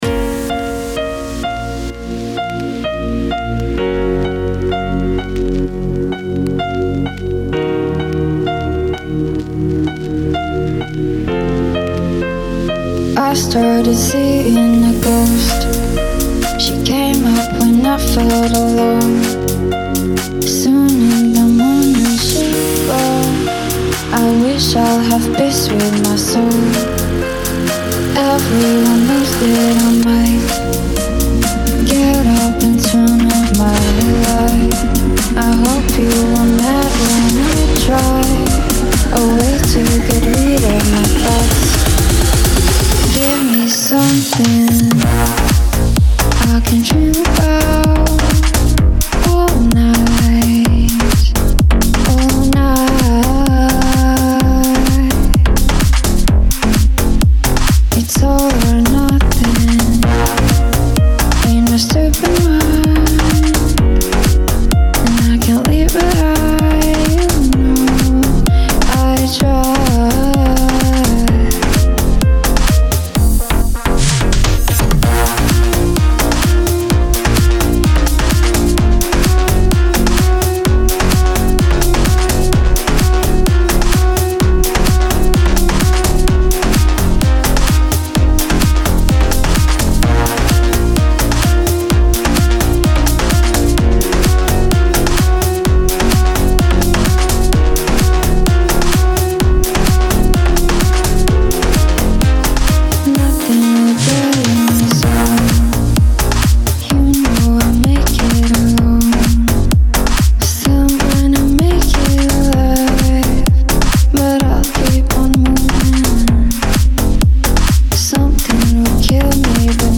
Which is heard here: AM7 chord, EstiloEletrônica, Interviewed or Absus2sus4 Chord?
EstiloEletrônica